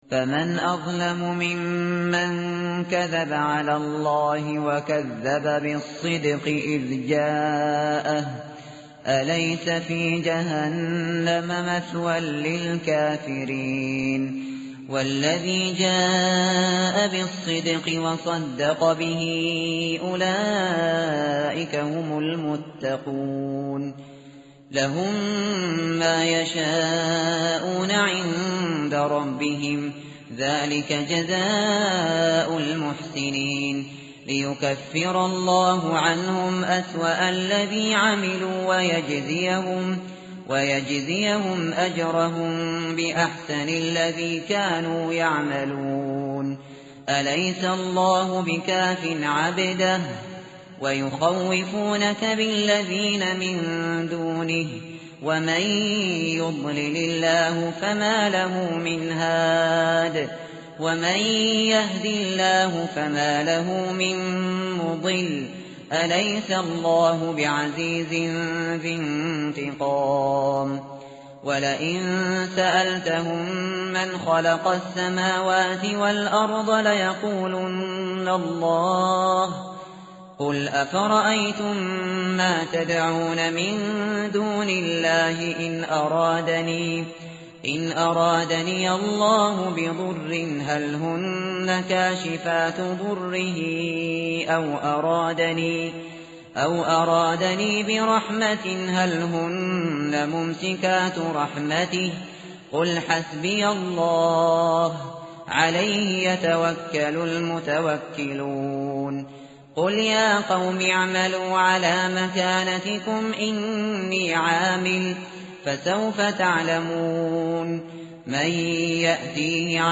متن قرآن همراه باتلاوت قرآن و ترجمه
tartil_shateri_page_462.mp3